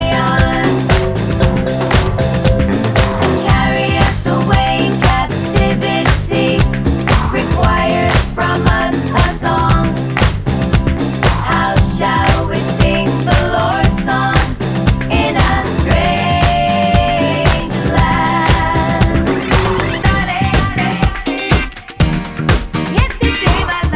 Female Solo Disco 3:43 Buy £1.50